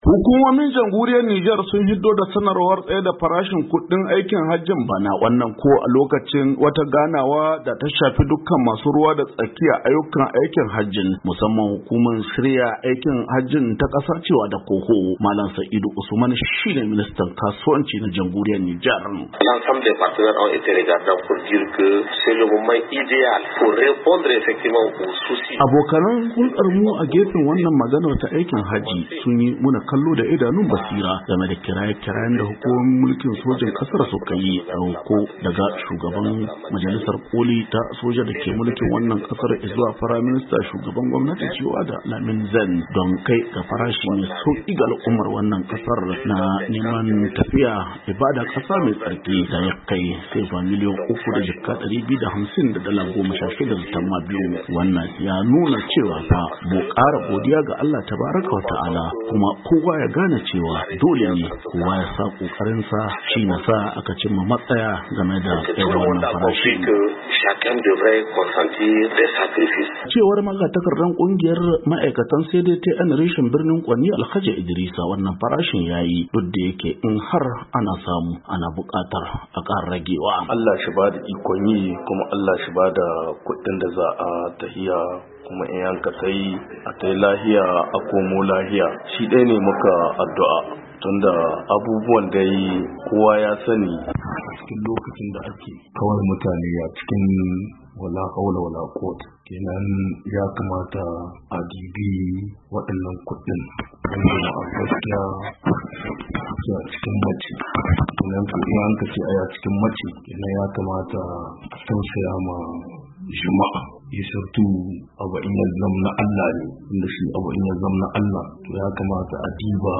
Malam Saidu Usman ministan kasuwanci na Jamhuriyar Nijar ya ce abokan huldar mu akan wannan maganar ta aikin hajji, sun yi mana kallo da idanun basira, game da kiraye-kirayen da hukumomin wannan kasar suka yi, tun daga shugaban majalisar koli ta soja dake mulkin wannan kasar, ya zuwa Firai Ministan Lamine Zeine, don kai ga farashi mai sauki ga al'ummar wannan kasar na neman tafiya ibada kasa mai tsarki da ya kai sama da sefa miliya uku.